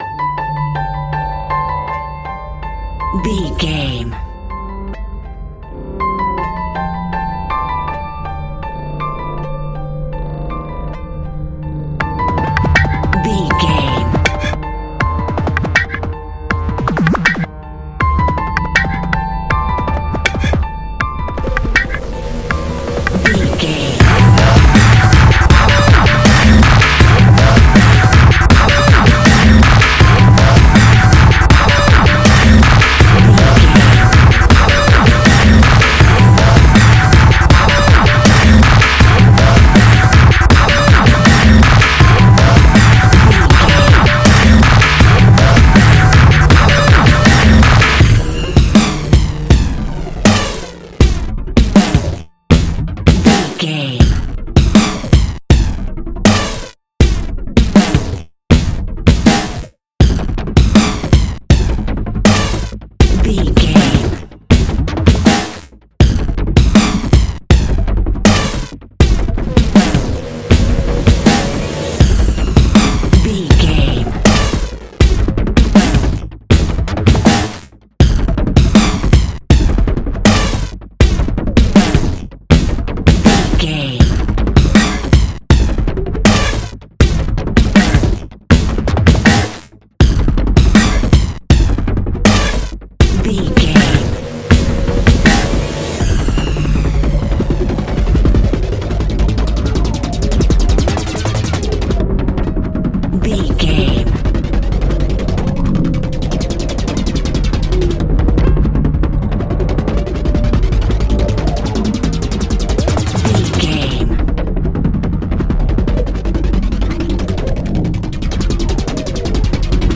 A great piece of royalty free music
Thriller
In-crescendo
Aeolian/Minor
Fast
aggressive
dark
hypnotic
industrial
heavy
drum machine
synthesiser
piano
breakbeat
energetic
synth leads
synth bass